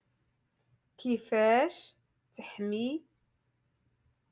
Moroccan Dialect- Rotation Five-Lesson Sixty Four